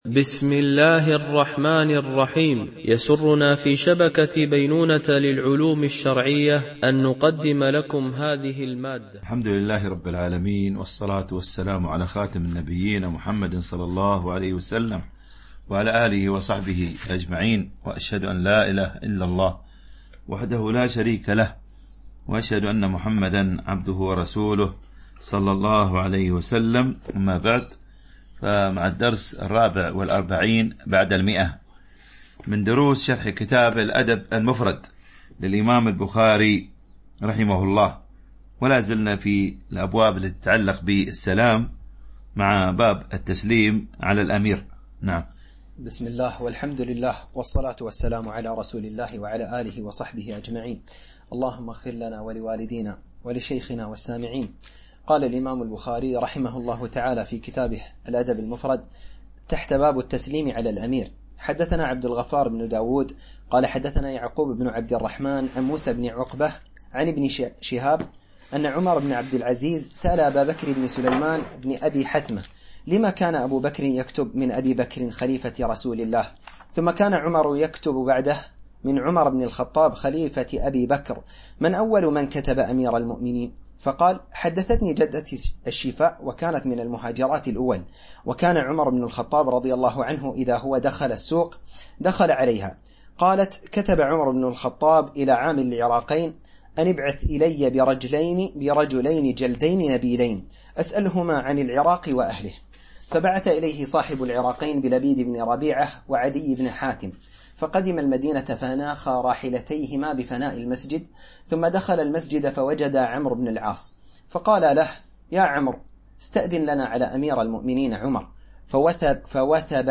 شرح الأدب المفرد للبخاري ـ الدرس 144 ( الحديث 1023 - 1037 )